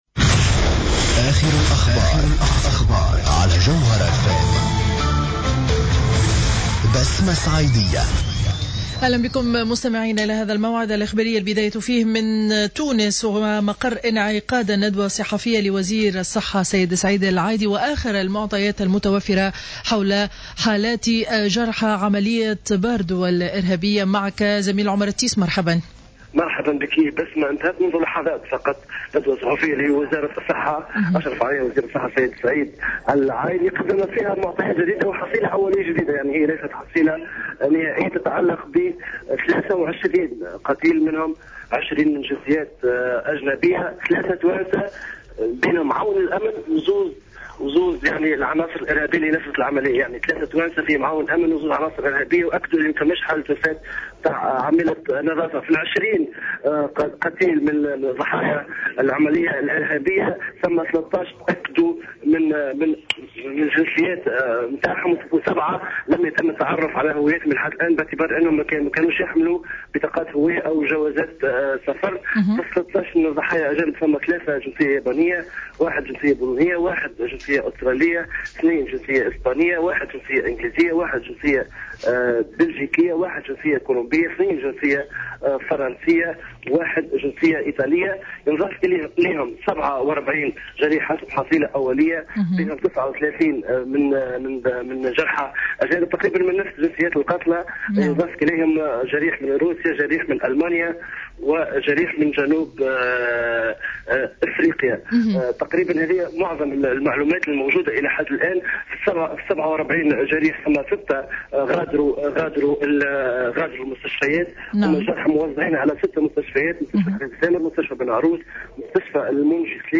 نشرة أخبار منتصف النهار ليوم الخميس 19 مارس2015